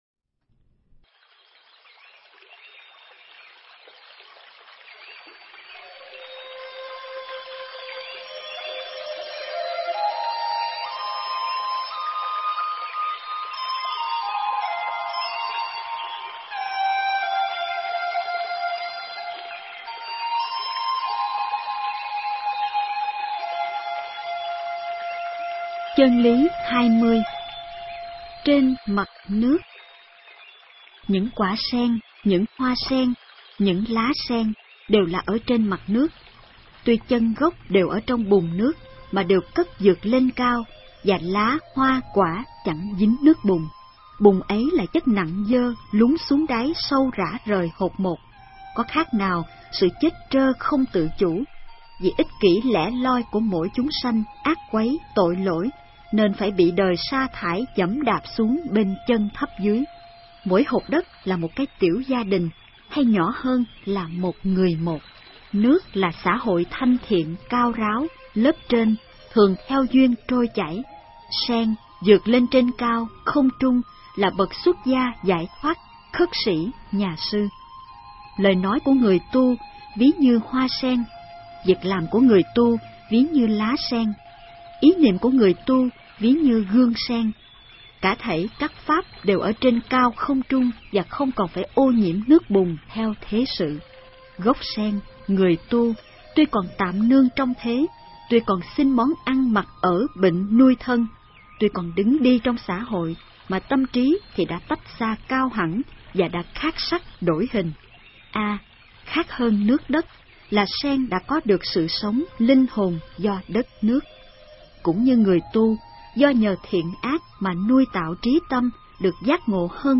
Nghe sách nói chương 20. Trên mặt nước